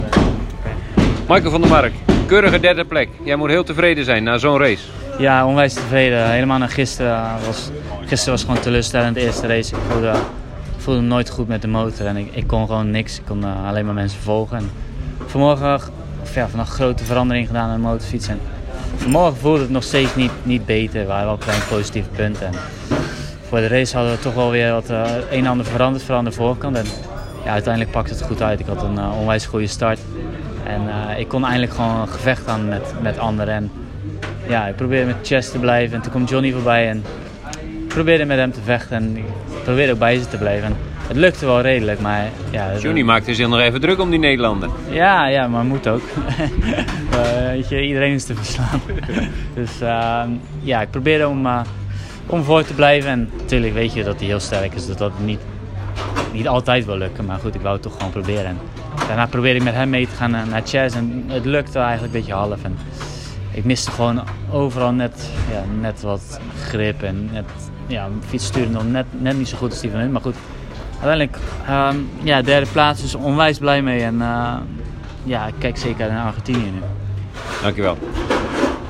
Direct na afloop zochten we de Pata Yamaha Official WorldSBK Team coureur op en vroegen hem naar een reactie.